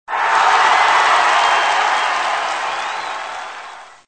34_cheerSound.mp3